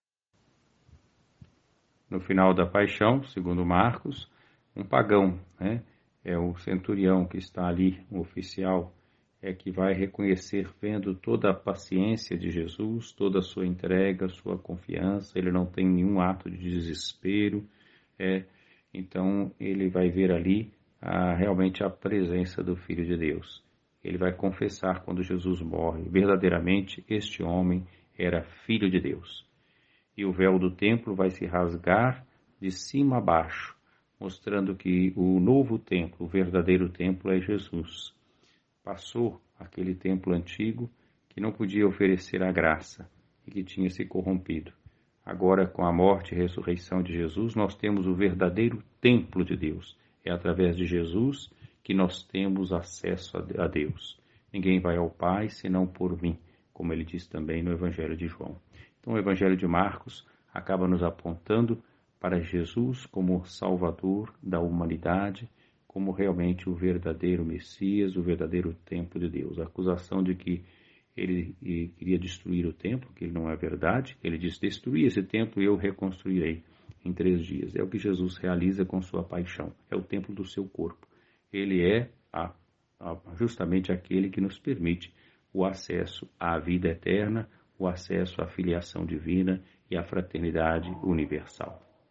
O significado dessa “reconstrução”, portanto, não é de uma igreja de pedra e sim de um coração renovado na fé e respeito a Deus. Acompanhe mais um áudio gravado pelo nosso Bispo Diocesano.